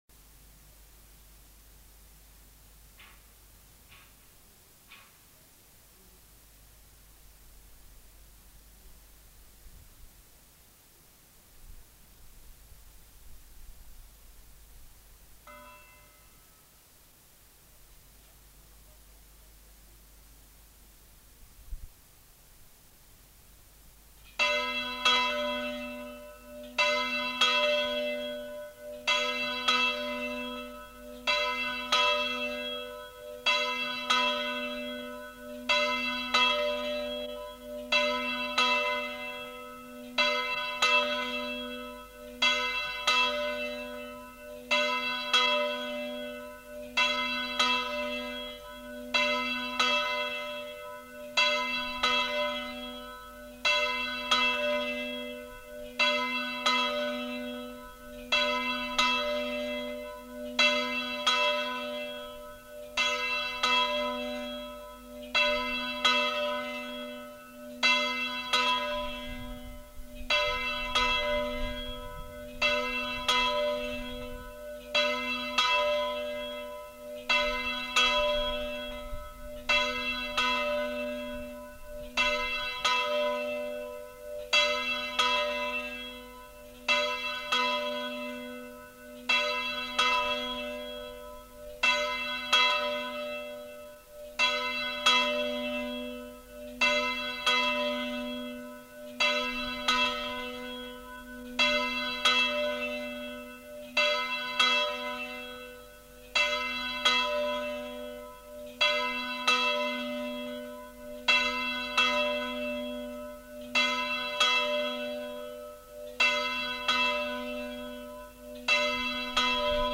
Sonnerie de veille de fête
Lieu : Buzan
Genre : paysage sonore
Instrument de musique : cloche d'église
Notes consultables : Sonnerie grosse cloche à la volée et petite cloche tintée.